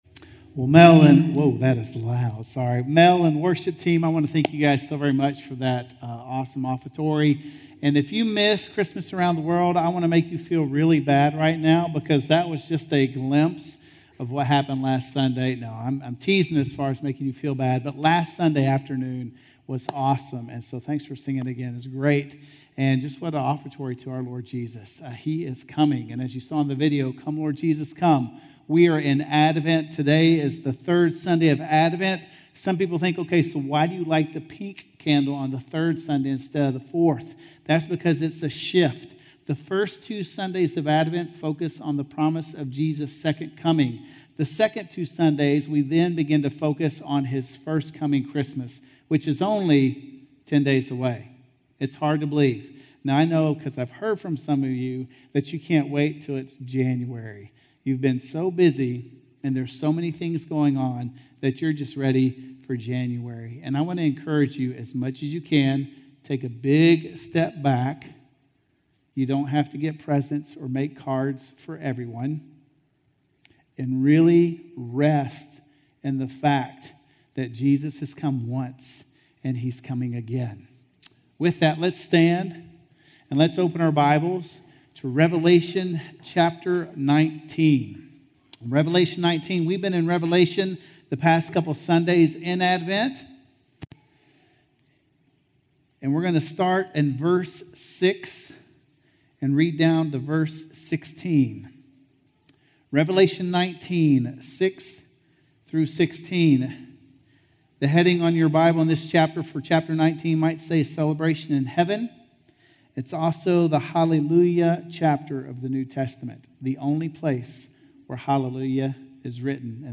The Last Battle - Sermon - Woodbine